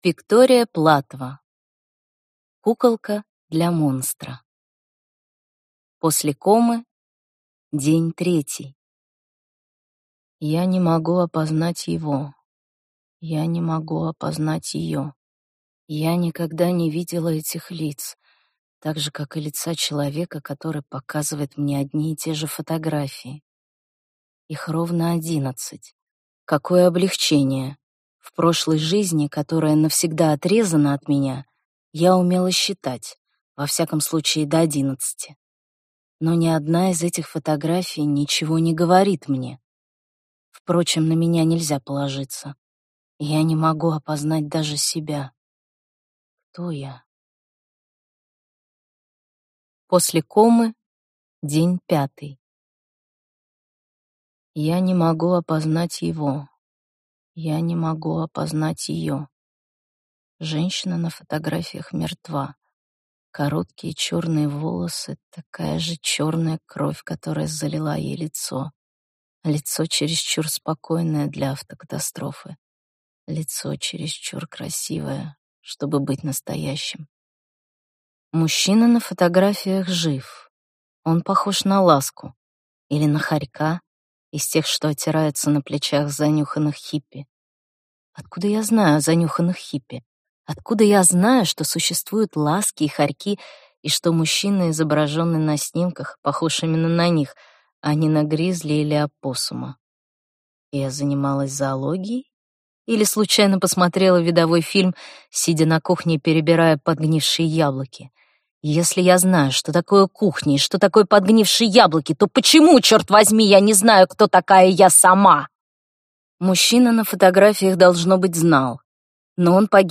Аудиокнига Куколка для монстра | Библиотека аудиокниг